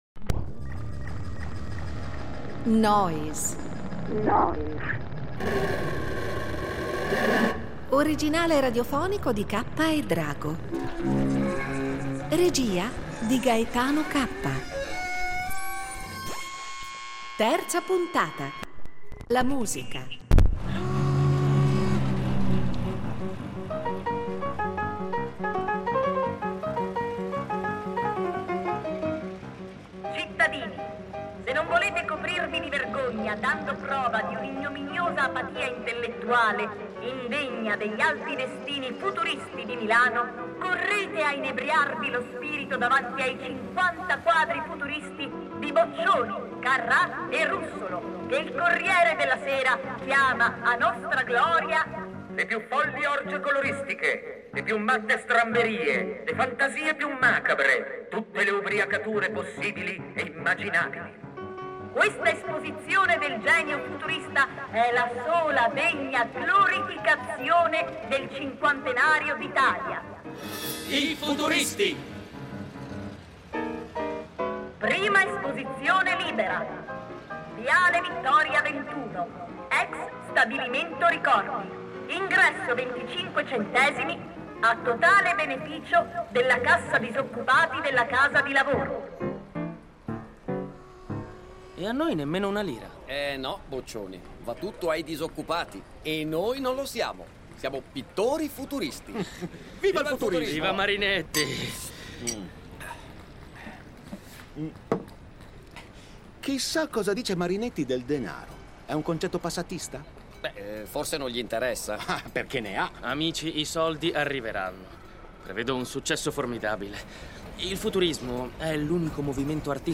Nel radiodramma abbiamo però privilegiato il Russolo musicista, compositore e inventore di strumenti sonori.